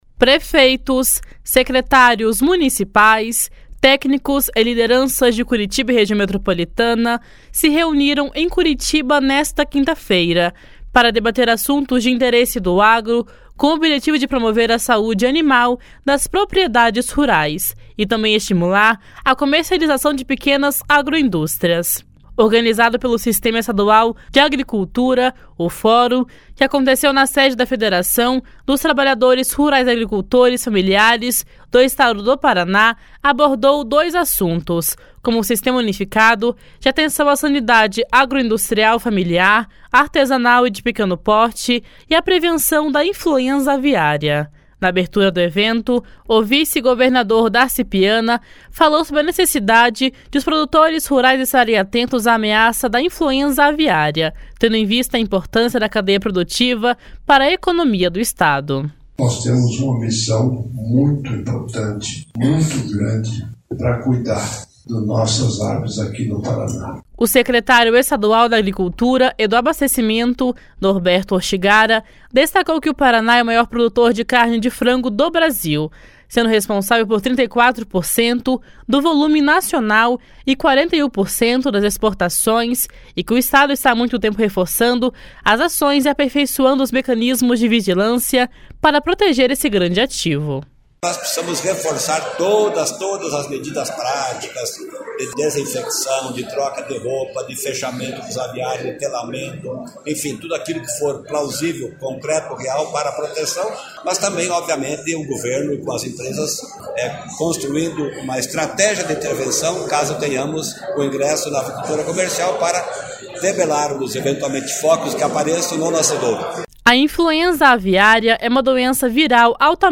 Na abertura do evento, o vice-governador Darci Piana falou sobre a necessidade de os produtores rurais estarem atentos à ameaça da influenza aviária, tendo em vista a importância da cadeia produtiva para a economia do Estado. // SONORA DARCI PIANA //
// SONORA NORBERTO ORTIGARA //